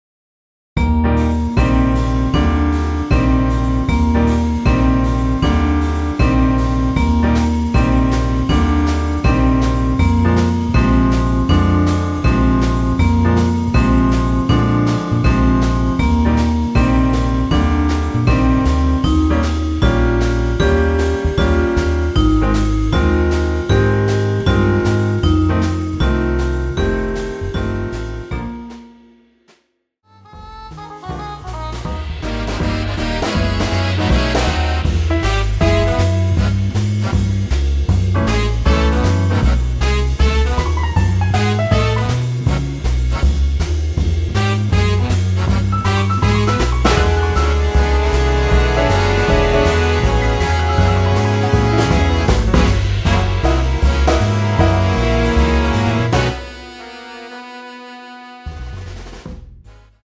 원키에서(-4)내린 MR입니다.
Eb
앞부분30초, 뒷부분30초씩 편집해서 올려 드리고 있습니다.
중간에 음이 끈어지고 다시 나오는 이유는